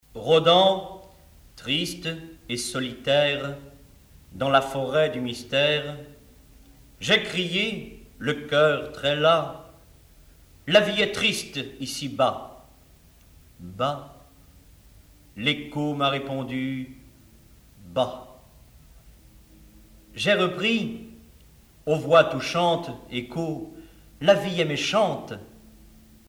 Genre récit
Catégorie Récit